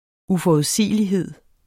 Udtale [ ufɒuðˈsiˀəliˌheðˀ ] Betydninger det at være uforudsigelig